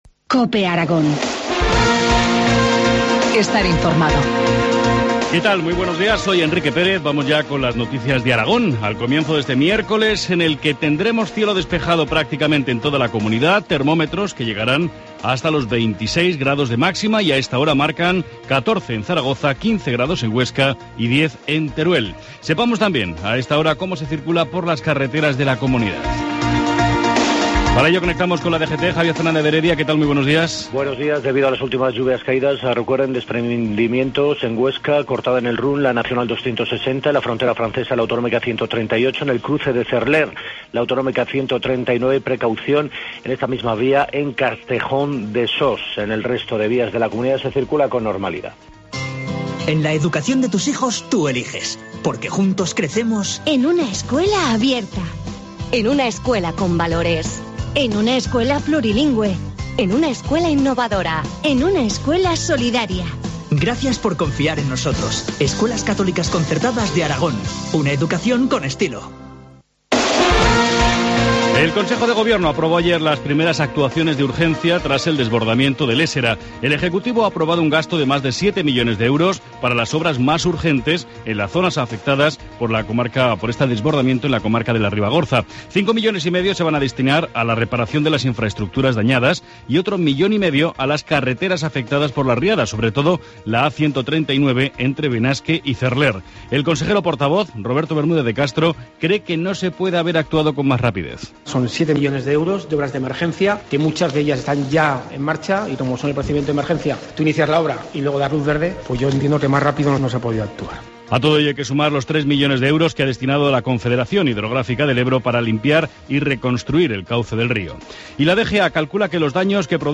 Informativo matinal, miércoles 26 de junio, 7.25 horas